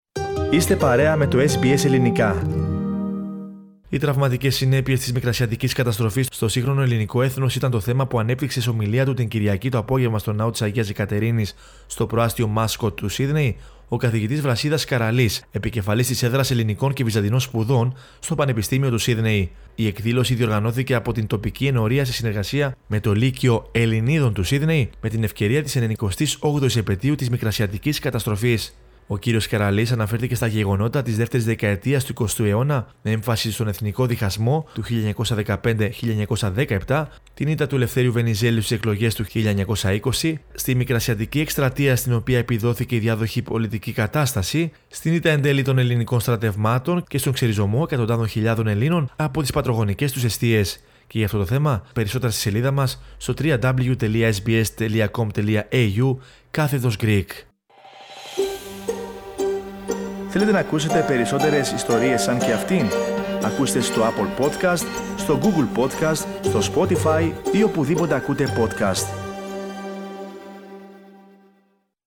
σε ομιλία του την Κυριακή το απόγευμα στον Ναό της Αγίας Αικατερίνης στο προάστειο Mascot του Σύδνεϋ